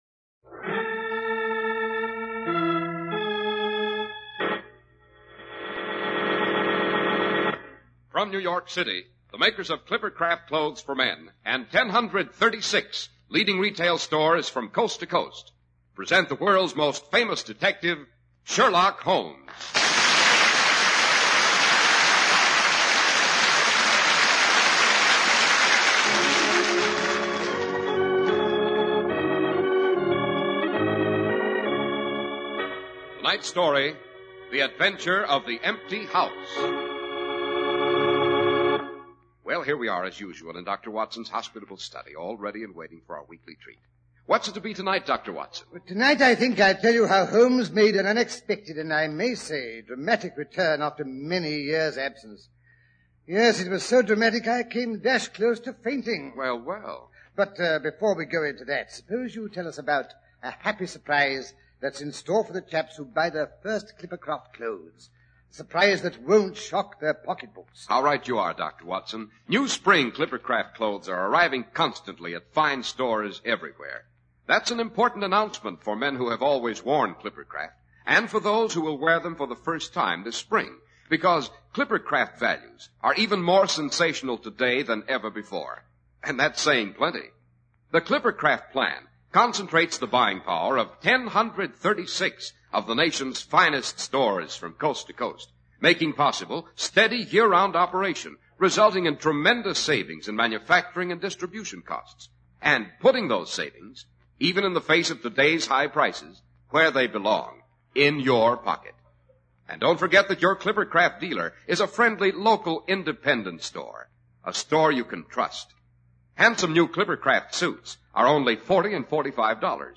Radio Show Drama with Sherlock Holmes - The Empty House 1948